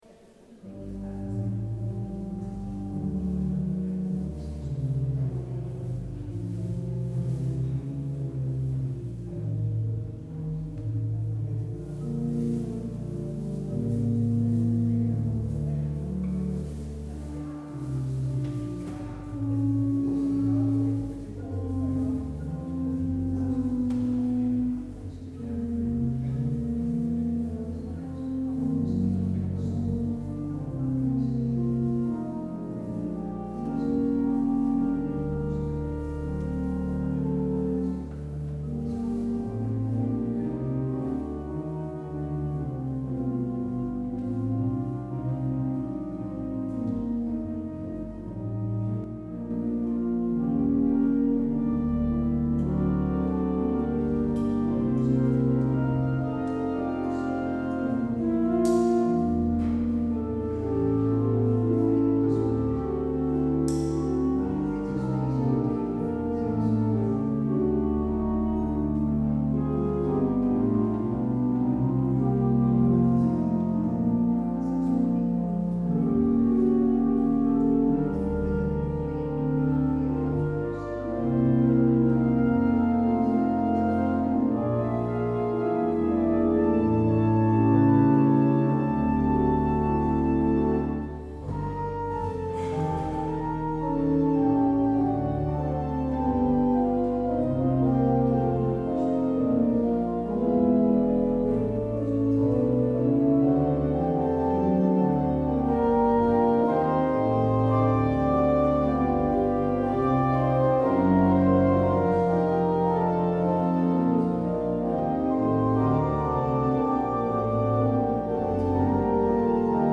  Luister deze kerkdienst hier terug: Alle-Dag-Kerk 16 juli 2024 Alle-Dag-Kerk https